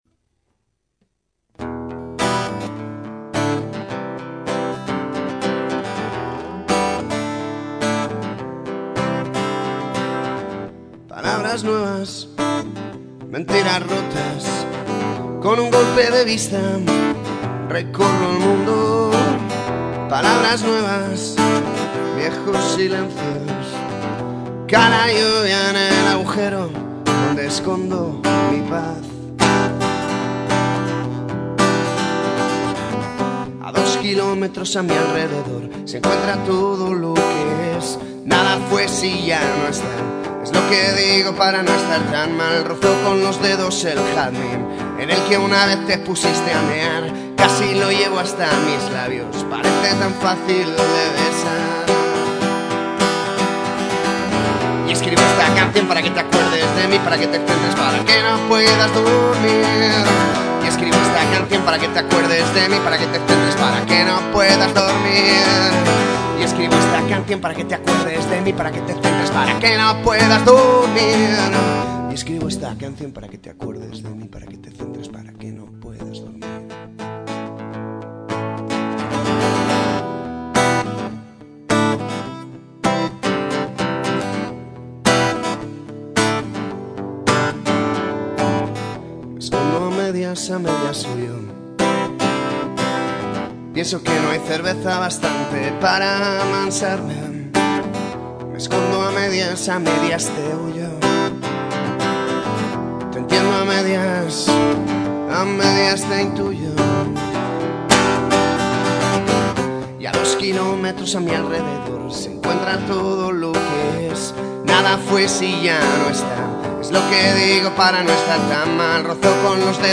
Recordando a todos que las canciones que subo aquí están recién compuestas y que, por tanto, no tienen rodaje ninguno (sonarán mejor con el tiempo, pero supongo que no las subiré después, por pereza), ahí va: